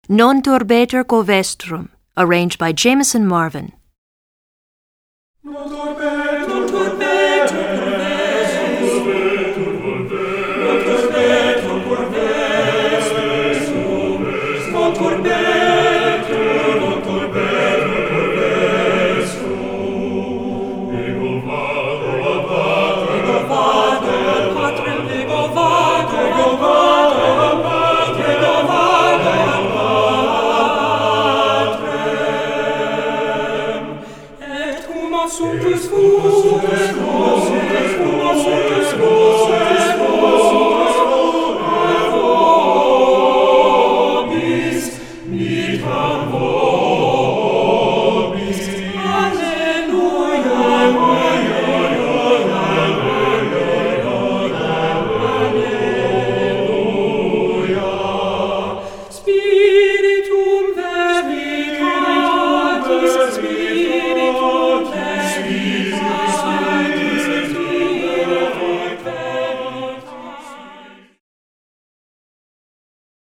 This joyous, festive motet
TBB